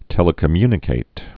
(tĕlĭ-kə-mynĭ-kāt)